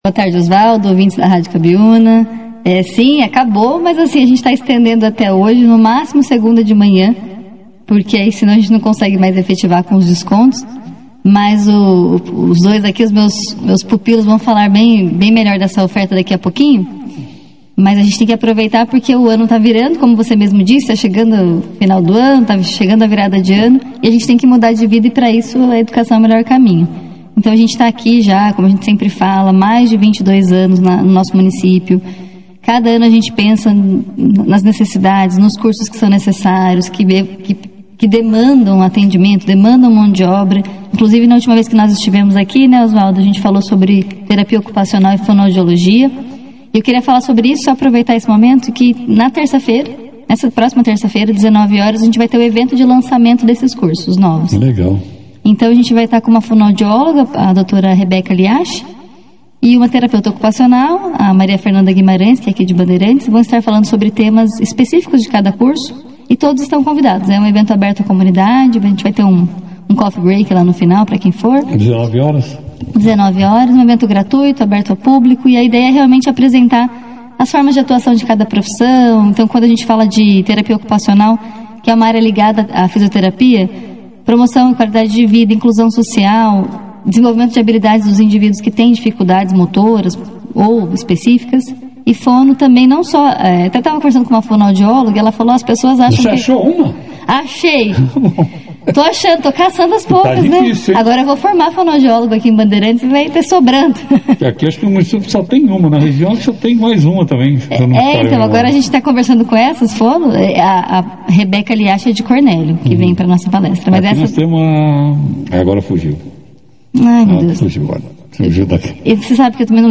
Durante a entrevista, a equipe destacou que a Unopar segue ampliando sua grade de cursos conforme a demanda de mão de obra da região. Entre as novidades, a instituição está lançando Terapia Ocupacional e Fonoaudiologia, duas áreas em expansão e com grande procura no setor de saúde.